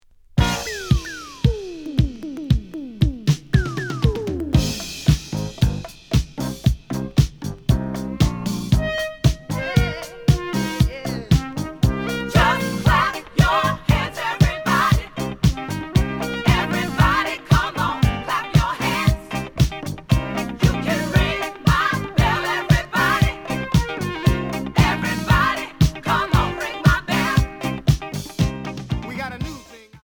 試聴は実際のレコードから録音しています。
●Genre: Disco
●Record Grading: VG+~EX- (プロモ盤。)